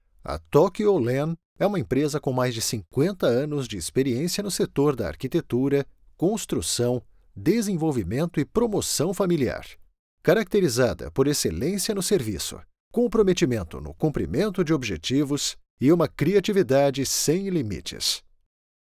Naturelle, Amicale, Fiable, Corporative, Accessible
Corporate